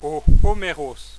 Ð “Omhroj, ou[o]
La prononciation ici proposée est la prononciation qui a cours actuellement, en milieu scolaire, en France.